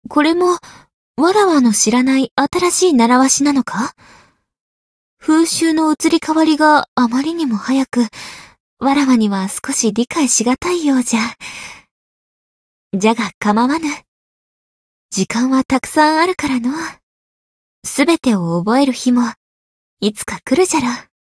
灵魂潮汐-蕖灵-情人节（摸头语音）.ogg